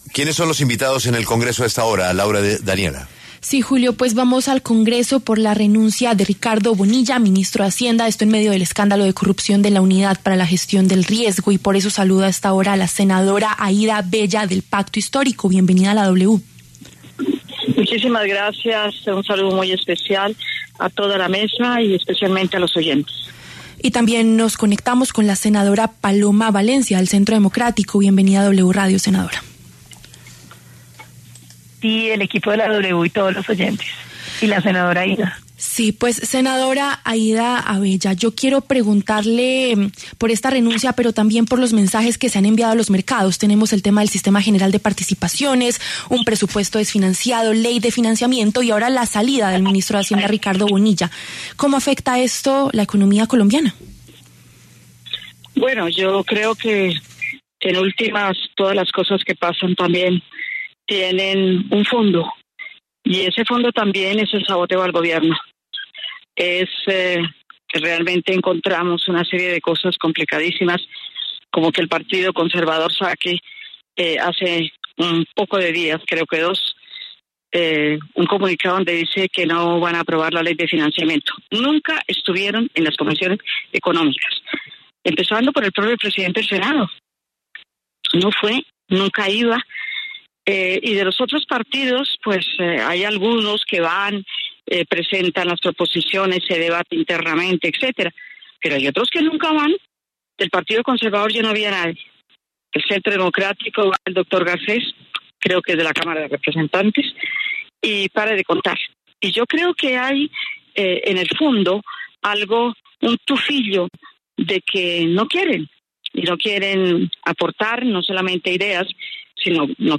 Debate de congresistas: ¿genera inestabilidad la renuncia de Ricardo Bonilla?
Las senadoras Aída Avella y Paloma Valencia se pronunciaron sobre la salida del ministro de Hacienda, Ricardo Bonilla, del Gobierno Petro.